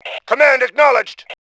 voice / Male5 / cmd.acknowledge.WAV
cmd.acknowledge.WAV